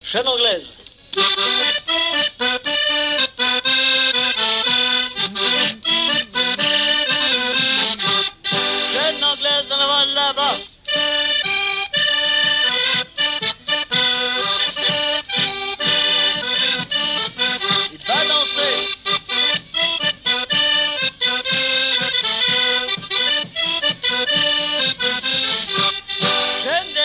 Saint-Pierre-du-Chemin
danse : quadrille : chaîne anglaise
Pièce musicale inédite